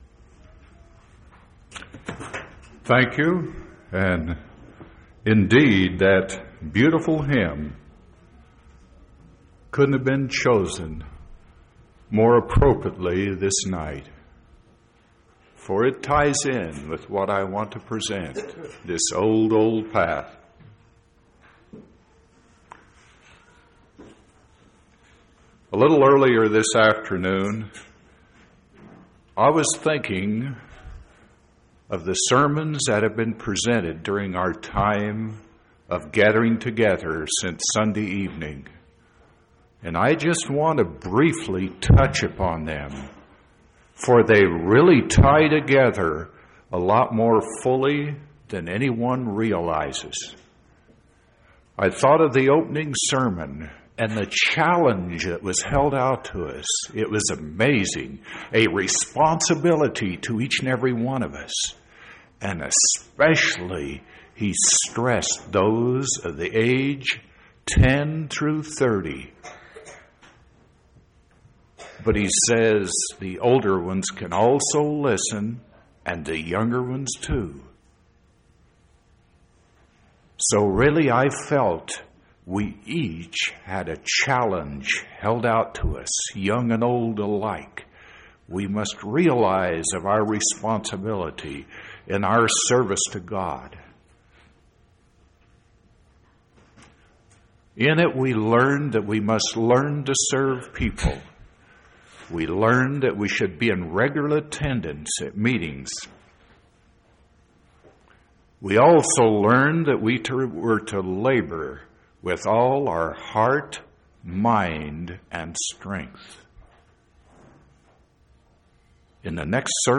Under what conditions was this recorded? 4/5/1987 Location: Temple Lot Local (Conference) Event: General Church Conference